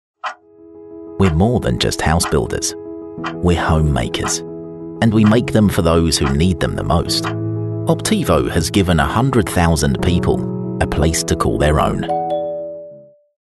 Warm Voice Over Artists | Page | Voice Fairy